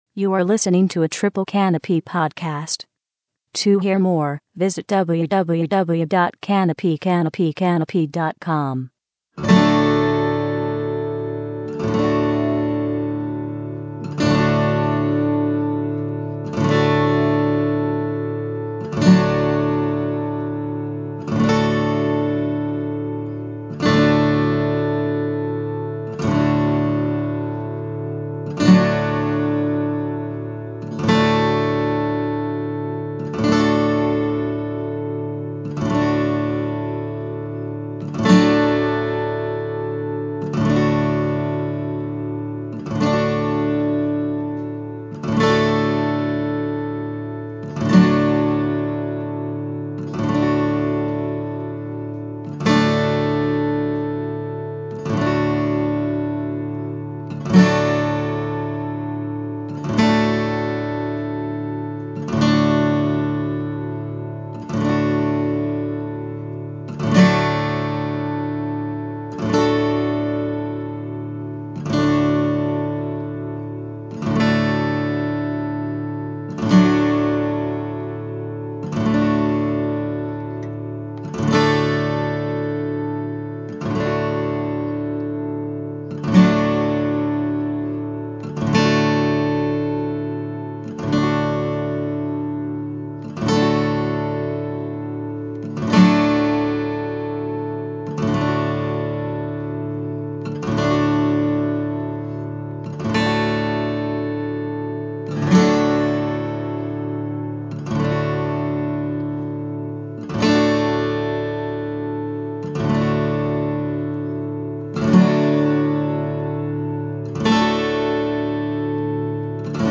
The program consisted of seven hours of sound work.